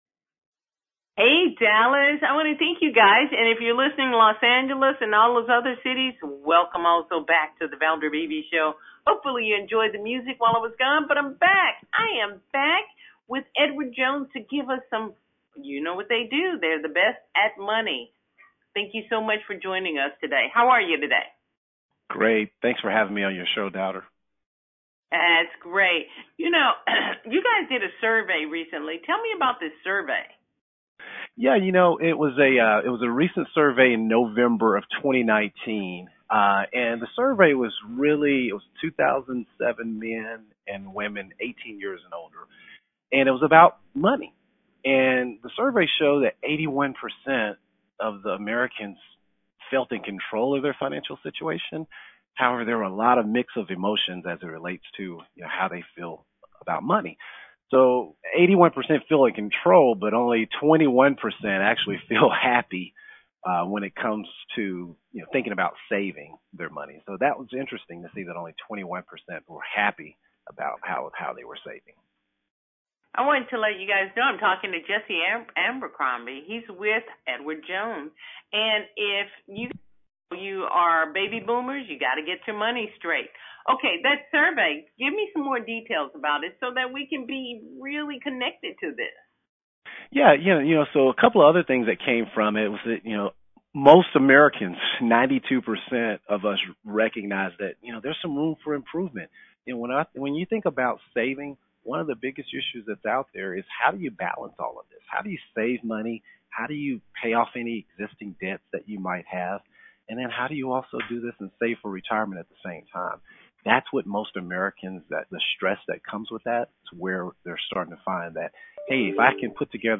ten minute live interview on KKVI-AM (Dallas, TX).